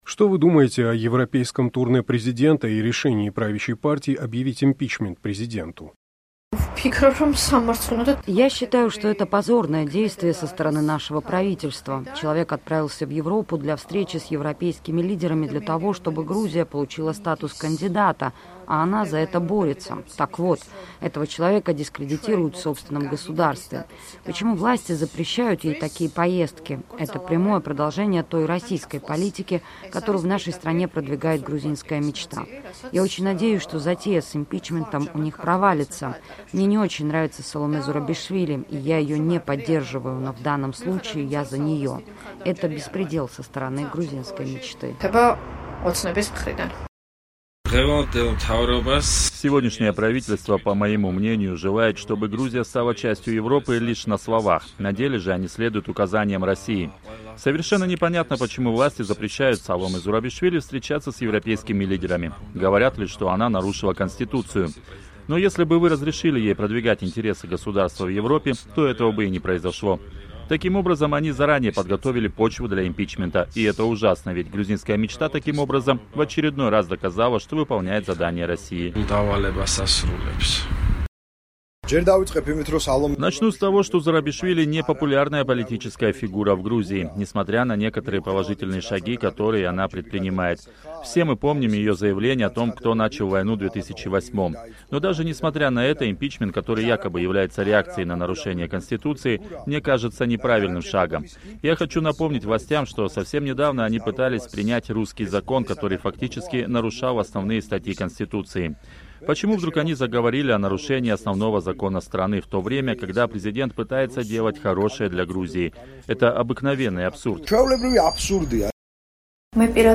На эту тему «Эхо Кавказа» пообщалось с пользователями социальных сетей.